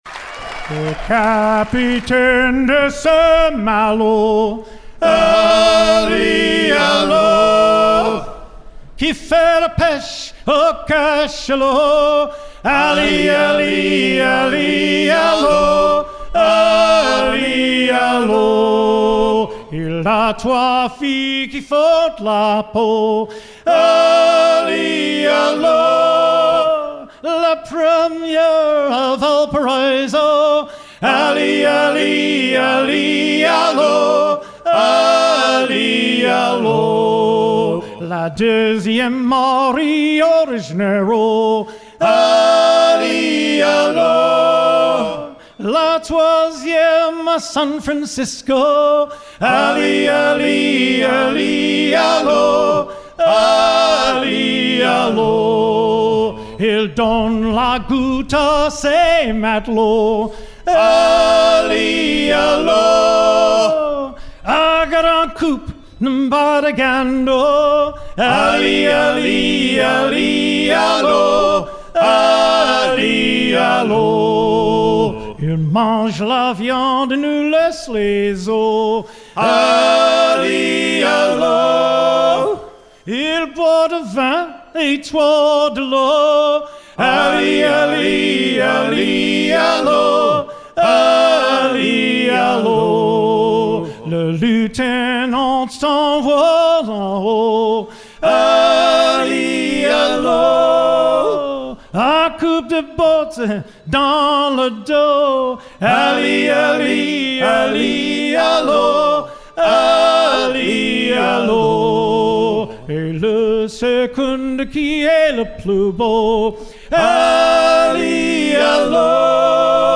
à hisser main sur main